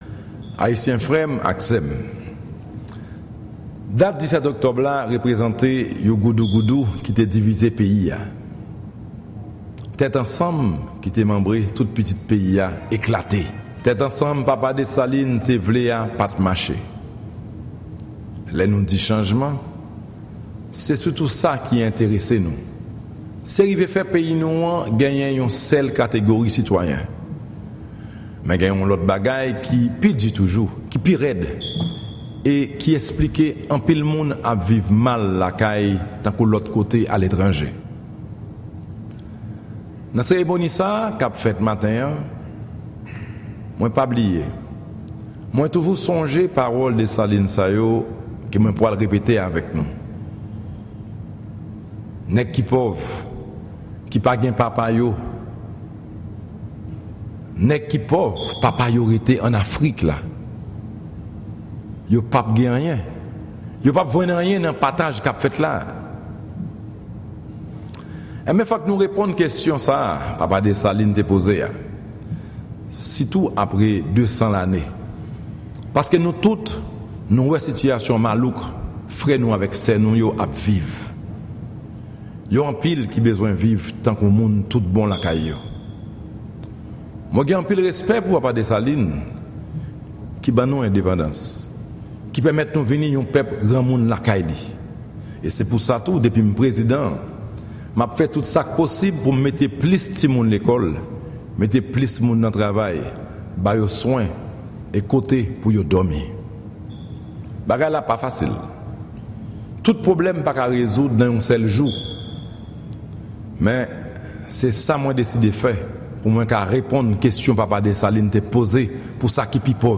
Ekstrè mesaj chèf deta ayisyen an, Michel Joseph Martelly